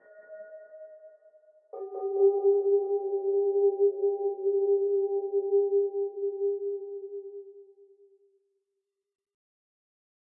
我用贝尔金公司的iPod麦克风适配器来录制。我录制的是我大学的锅炉房的声音。我用Sony sound forge来处理它（在它上面应用了EQ，增加了混响，去掉了一些噪音），最后用Wave Art插件TrackPlug5来改变它的物理感觉< /pgt。
标签： 竞争 效果 电影 处理 隆隆声
声道立体声